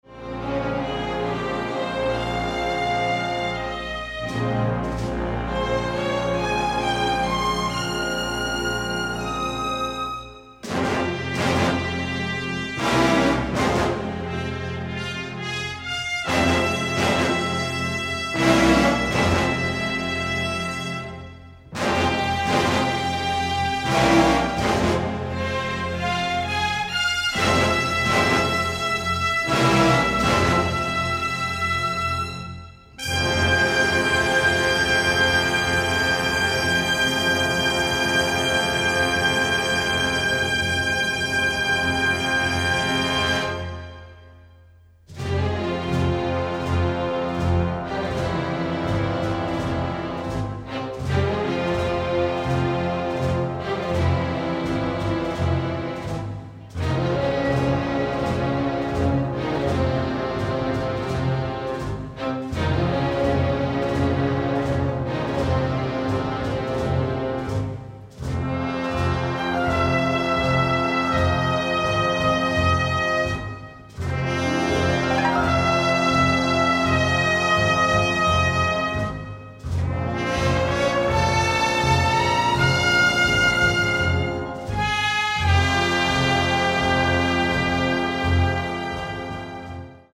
thrilling, often romantic score